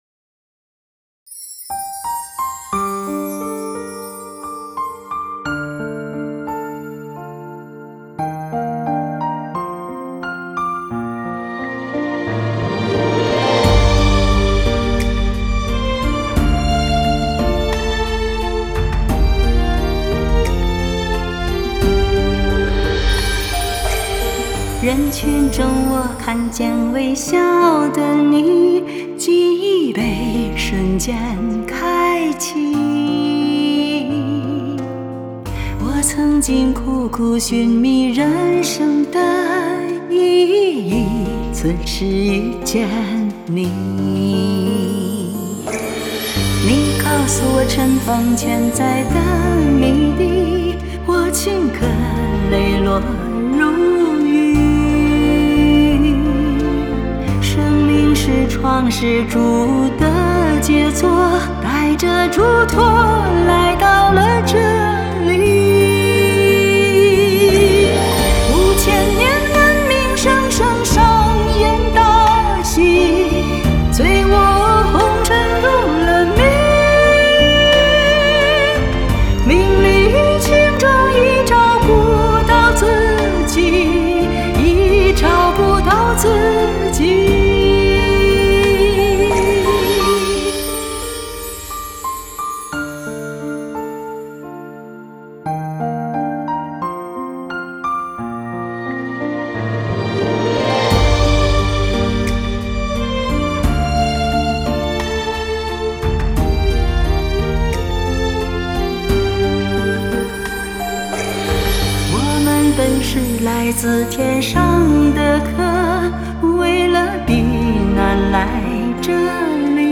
【慶祝513】女聲獨唱：天意 | 法輪大法正見網